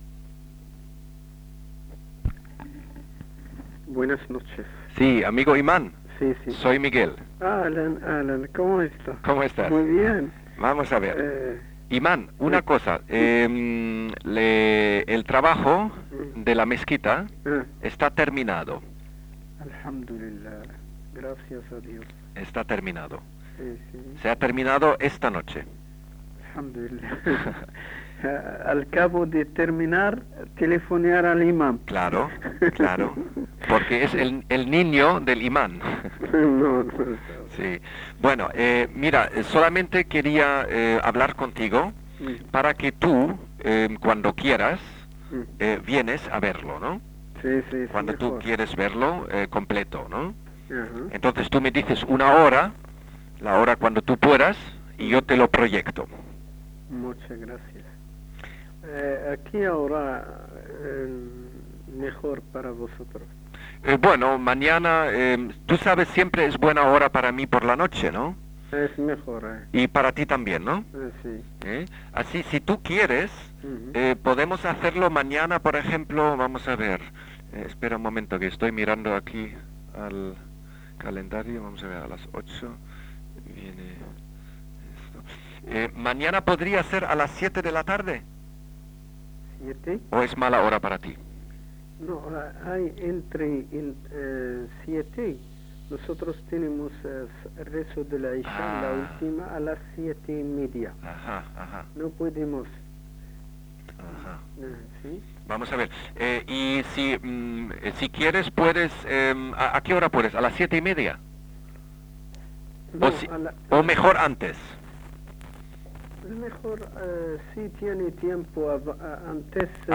Conversación A   /   Conversación B